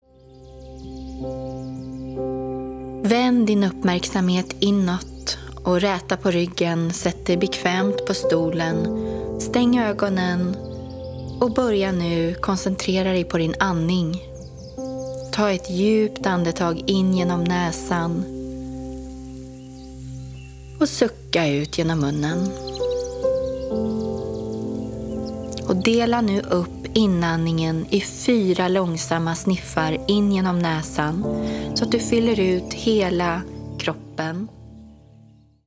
33 minuter lång meditation till lugnande musik för andning, koncentration och styrka för resten av dagen.